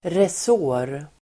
Ladda ner uttalet
Uttal: [res'å:r]